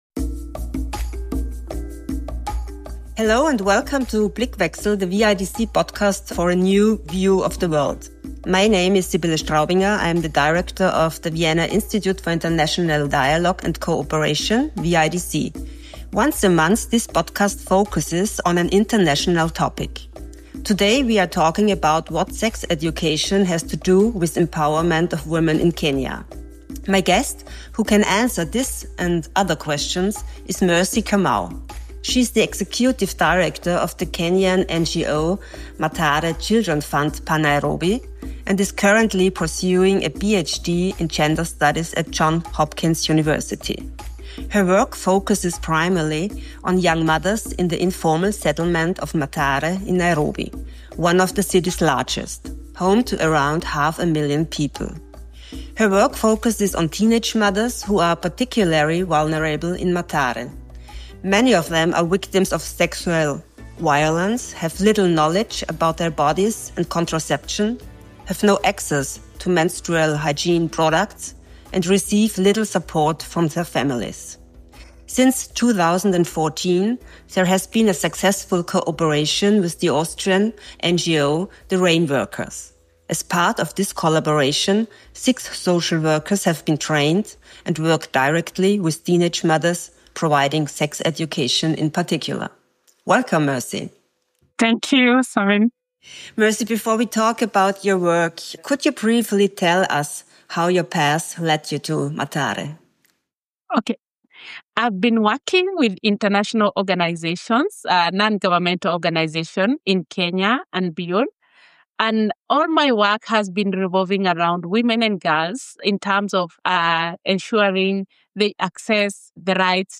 An inspiring conversation about the fight for gender justice in Kenya, the changing landscape of development funding, and what global actors can do to make gender programs more effective and transformative.